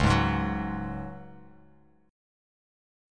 C#sus4 chord